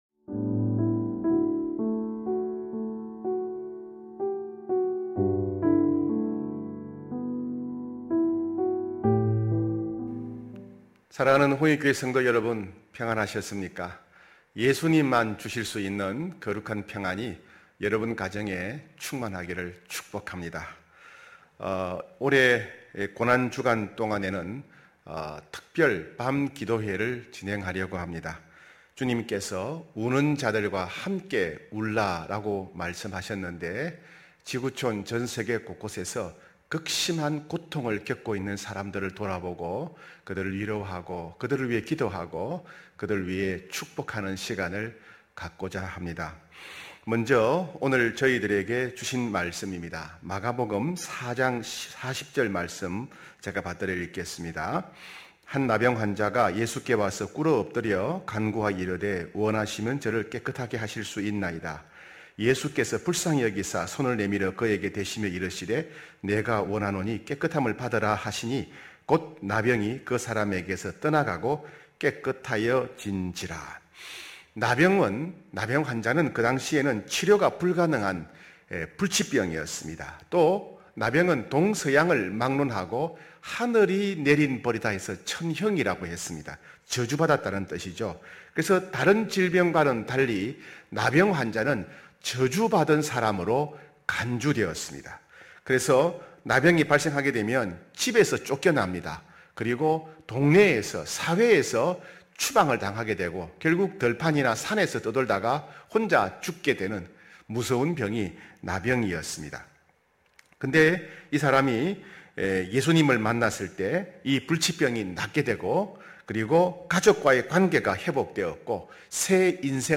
고난주간 특별 밤 기도회(3월29일).mp3